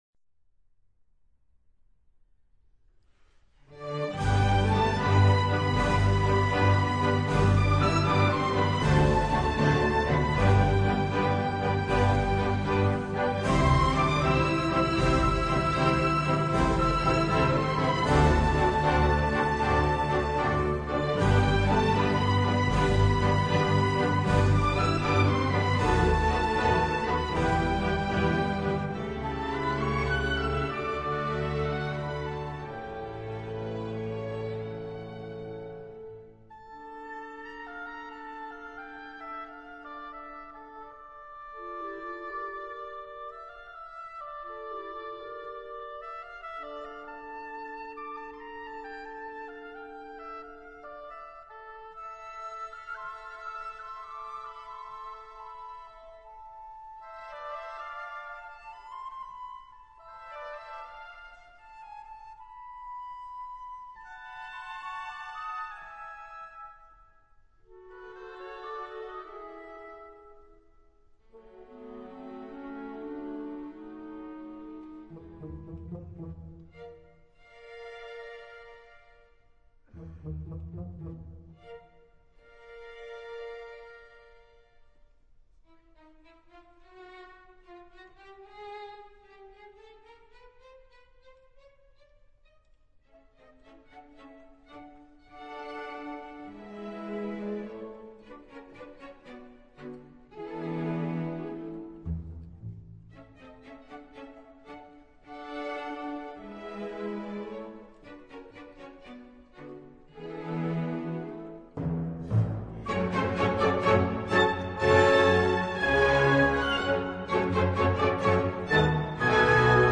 大提琴
音樂類型：古典音樂
Grand Concerto pour violoncello et orchestre